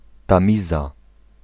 Ääntäminen
Ääntäminen UK US Tuntematon aksentti: IPA : /tɛmz/ river in Connecticut: IPA : /θeɪmz/ Haettu sana löytyi näillä lähdekielillä: englanti Käännös Ääninäyte Erisnimet 1.